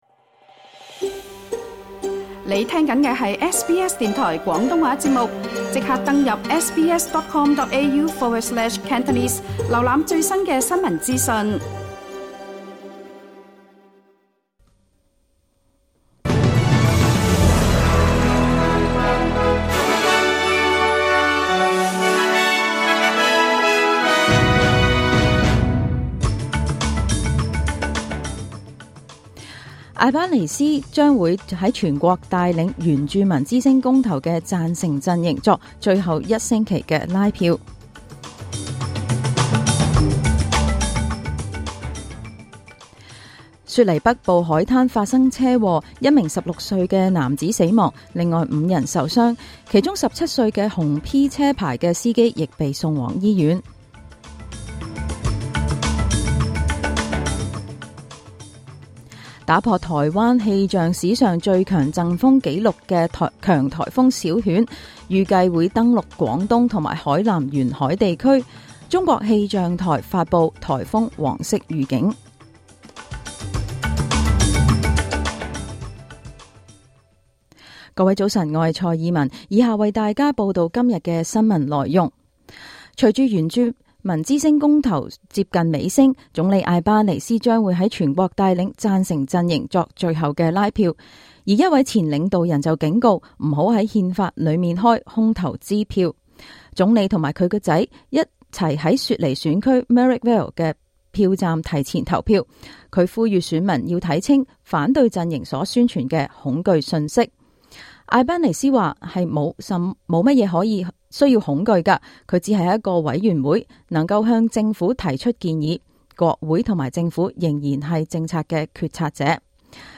請收聽本台為大家準備的早晨新聞。